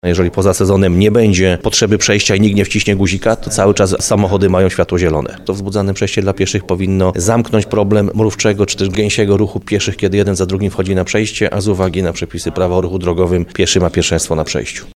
– Ma to poprawić komfort i bezpieczeństwo przechodniów – mówi burmistrz Krynicy-Zdroju Piotr Ryba.